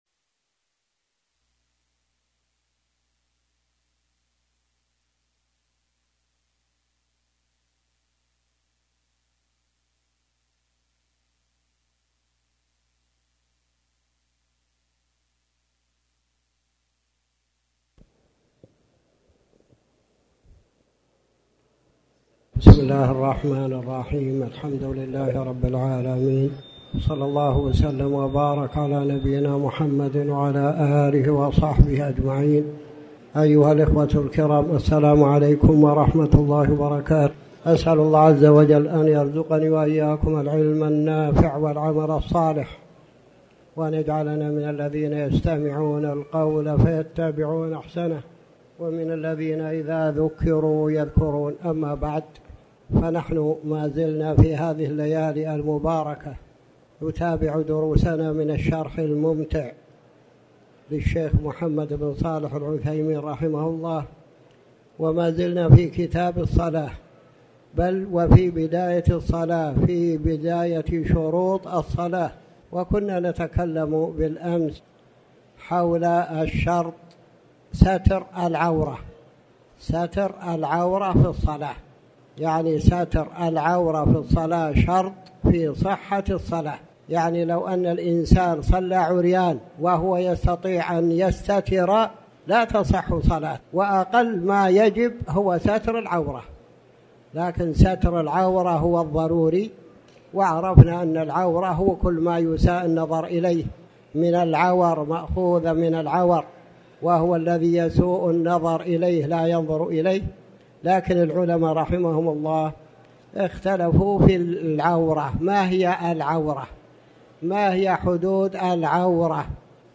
تاريخ النشر ٢٩ صفر ١٤٤٠ هـ المكان: المسجد الحرام الشيخ